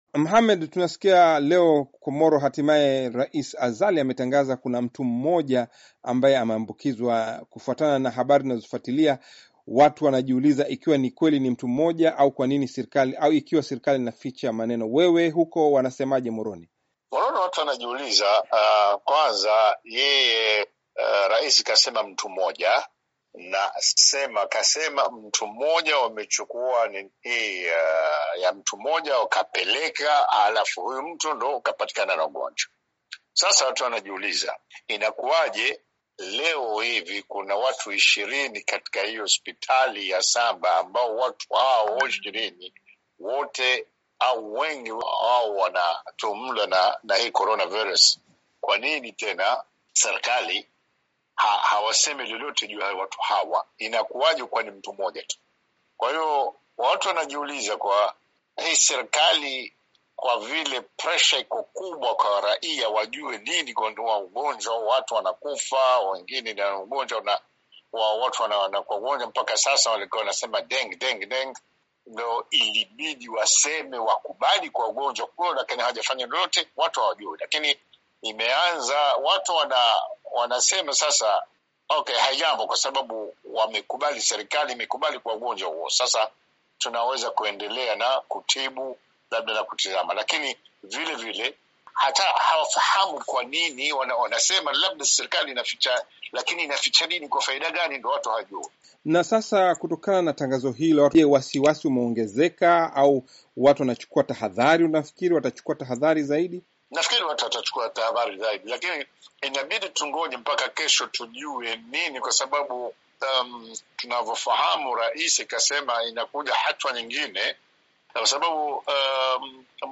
Mahojiano
Sauti